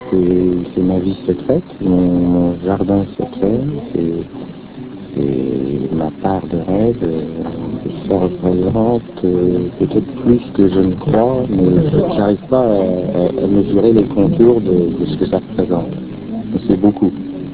Extracts from France Inter radio show "Les Visiteurs du Noir"